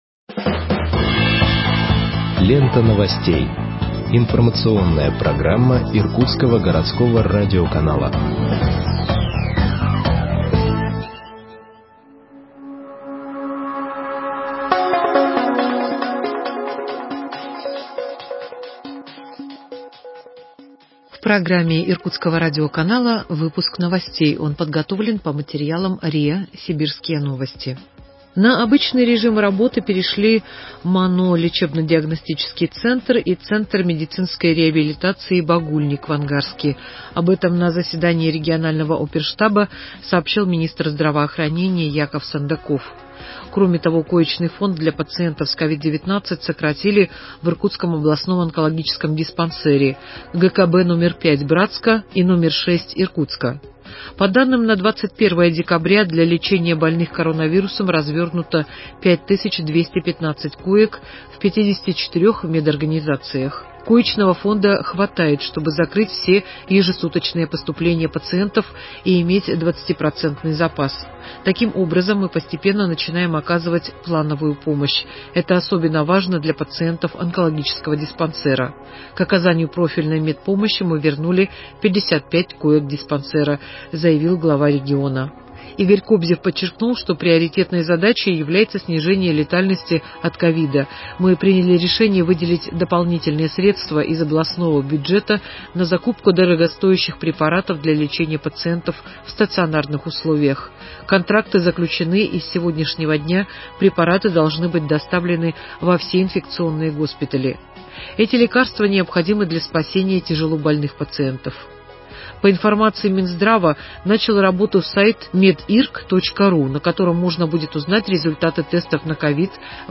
Выпуск новостей в подкастах газеты Иркутск от 23.12.2020 № 1